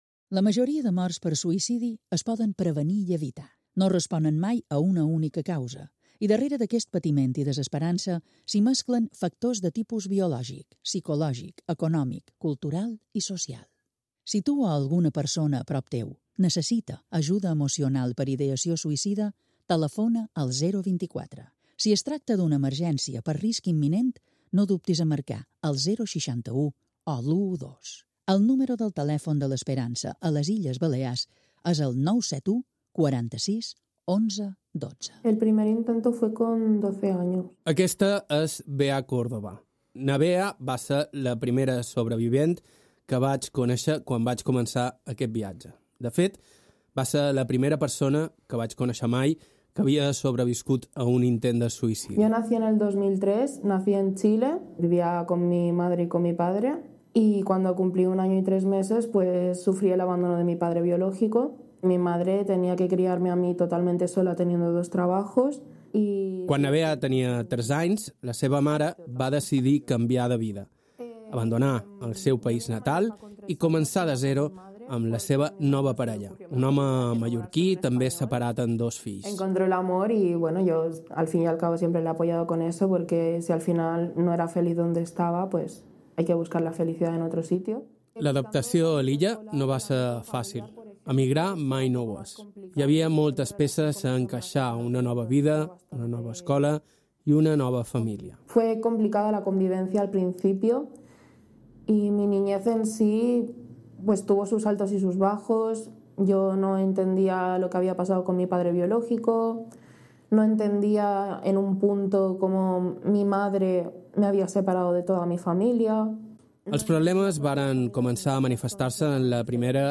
Les Desaparicions és una minisèrie documental en format pòdcast narratiu de cinc capítols de 50 minuts, produïda per IB3 Ràdio i Baste...